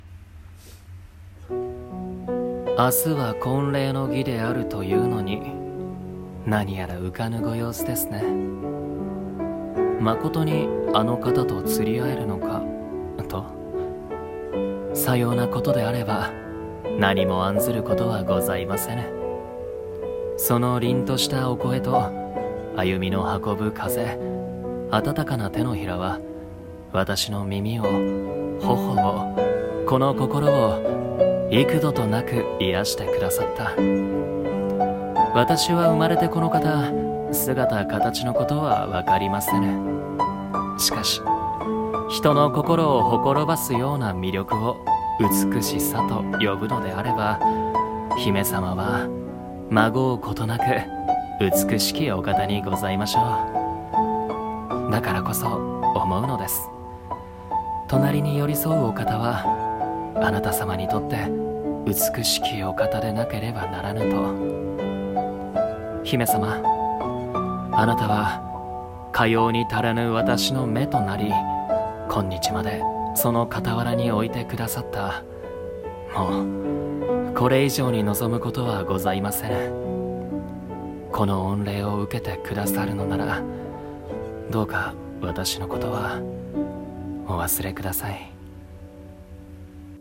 【和風台本】美しき、【一人声劇】